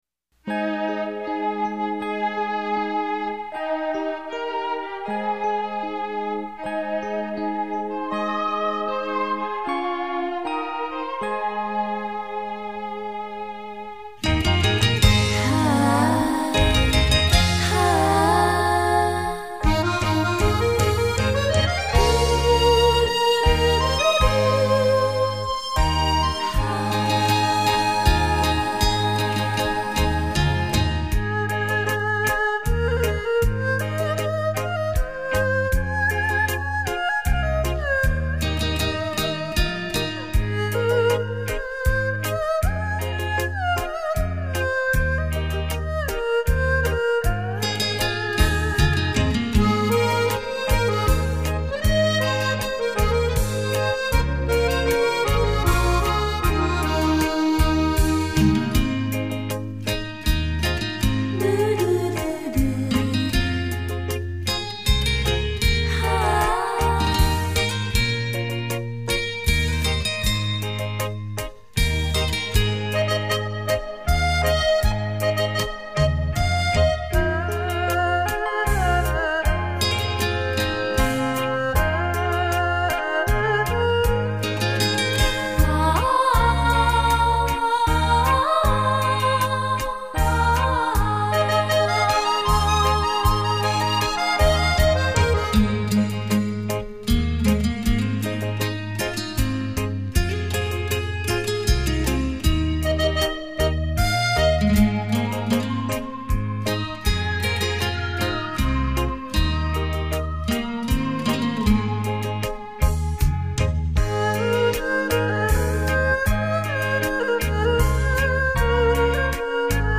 音质超好！